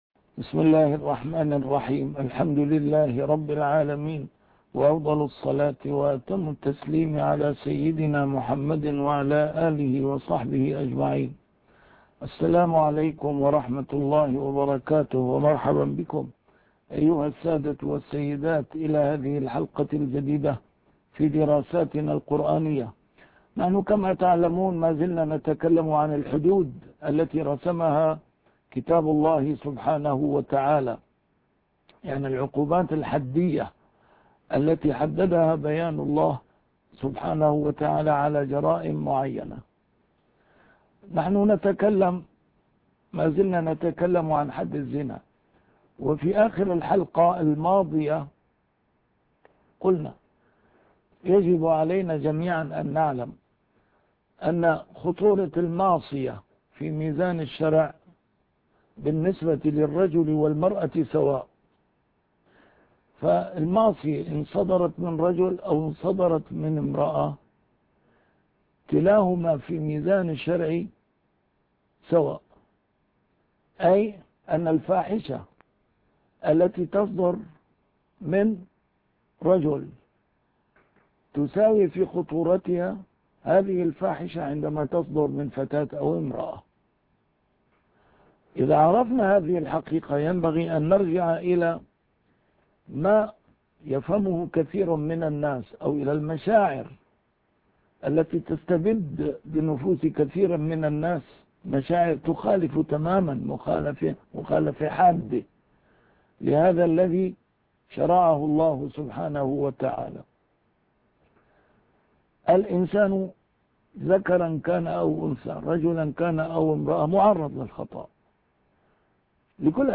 A MARTYR SCHOLAR: IMAM MUHAMMAD SAEED RAMADAN AL-BOUTI - الدروس العلمية - درسات قرآنية الجزء الثاني - 7- عقوبات الحد في القرآن الكريم